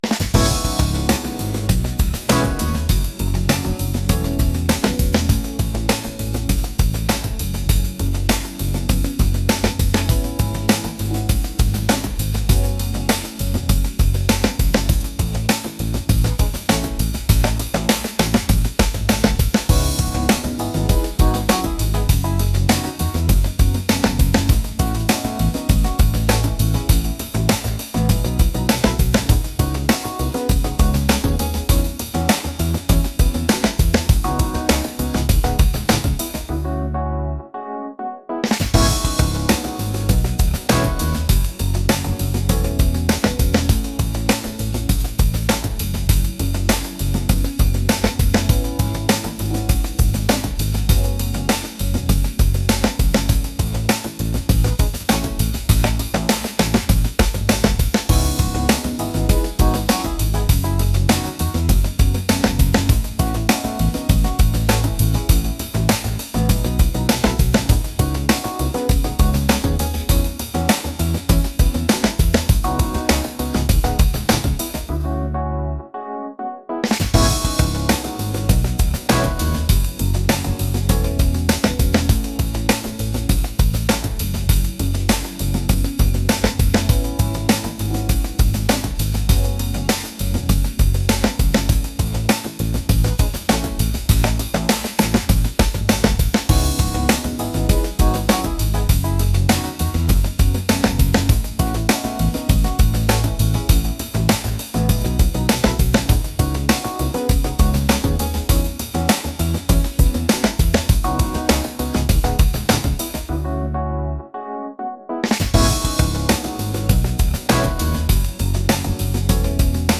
funky | fusion | jazz